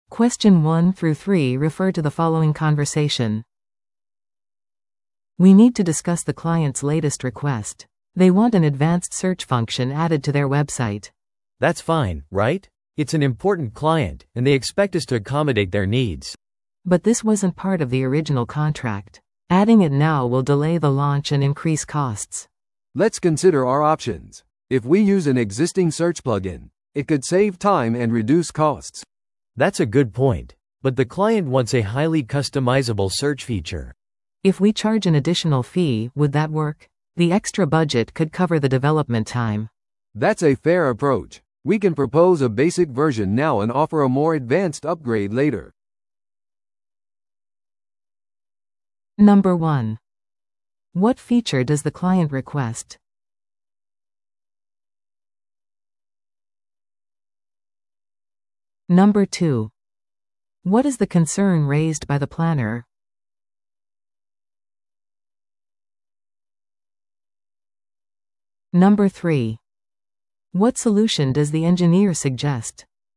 TOEICⓇ対策 Part 3｜クライアントのウェブサイト改良要求の議論 – 音声付き No.86